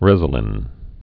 (rĕzə-lĭn)